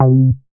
MoogPoint 007.WAV